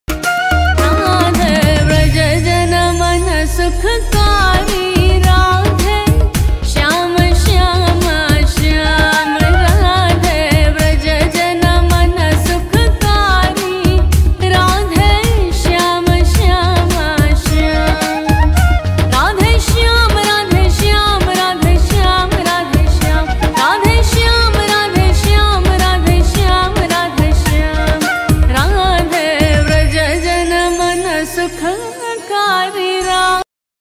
bhakti ringtone mp3